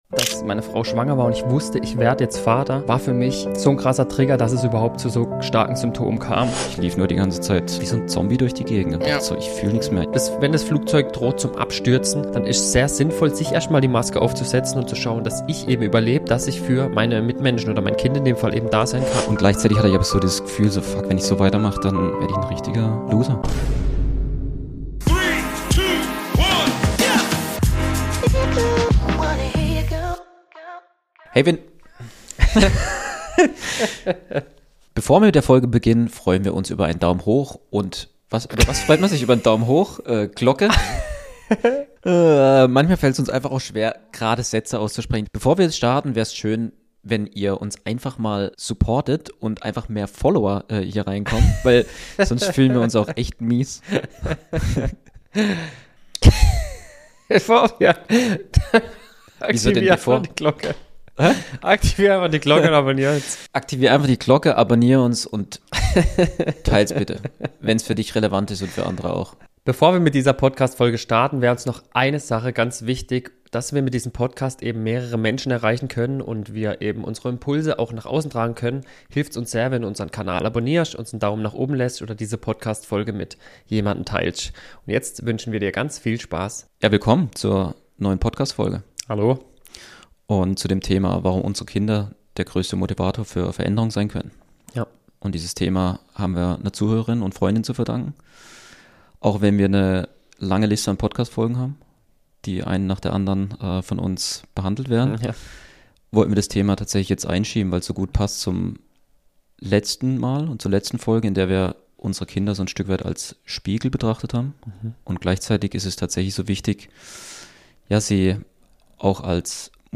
Beschreibung vor 5 Monaten Zwei Männer – ehrlich, direkt, ohne Ausreden. In diesem Gespräch geht’s um Verantwortung, Reife und warum Vatersein kein Titel, sondern ein täglicher Anspruch ist.